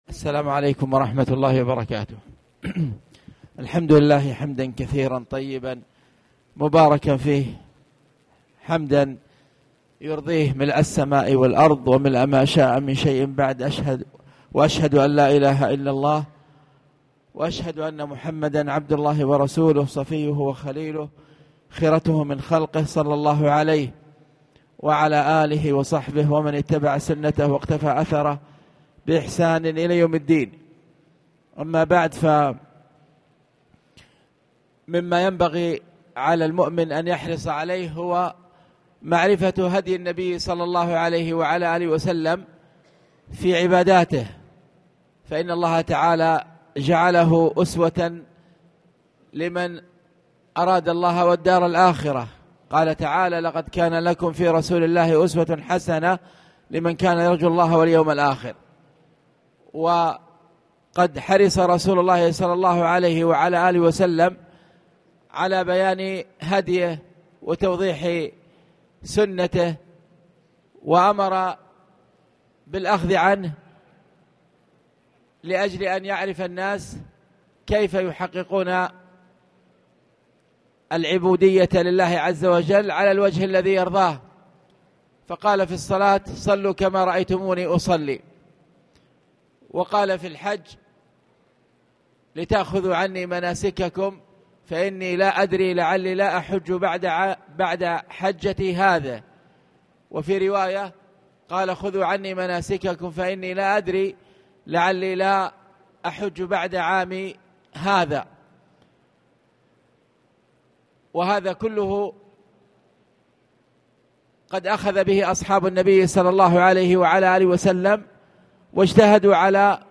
تاريخ النشر ٢٩ شوال ١٤٣٨ هـ المكان: المسجد الحرام الشيخ